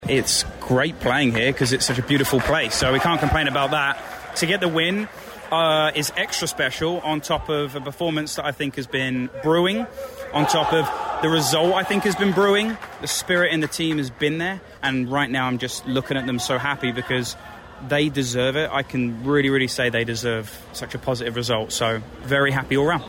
talked to WLEN Sports about what it was like to play a game in the dome, and to pick up a win in the ‘Dawgs conference opener…